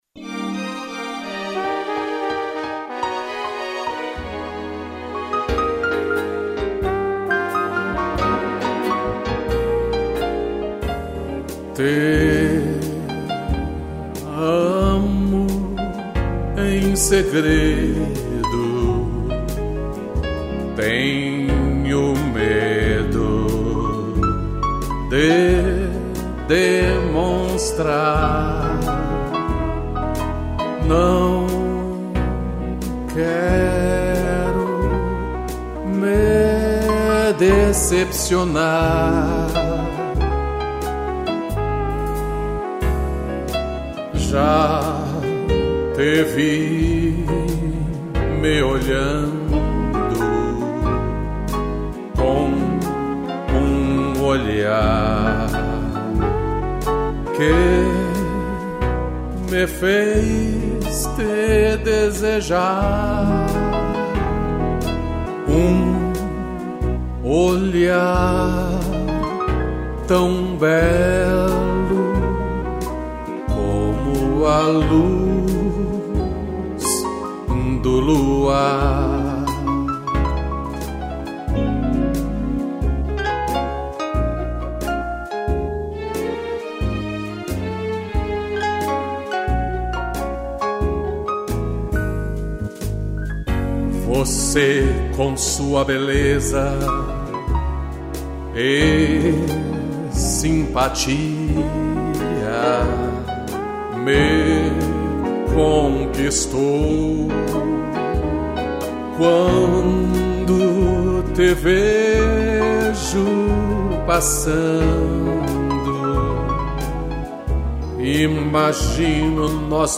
piano e trompete